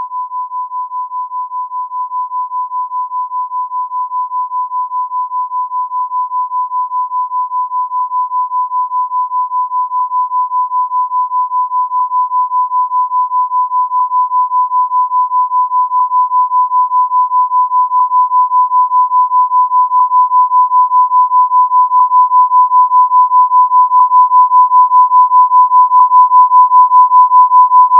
Clipping Test
999 Hz sine wave, -2 dB FS (undistorted reference), 0, +1 ,2, 3, 4, 5, 6, 7, 8, 9, 10, 11, 12 dbFS. 2 seconds each step.
Input signal to specially modified encoder. This signal is 12 dB lower than the bitstream values produced by the modifed encoder.
ClippingTest2_0-12dB.wav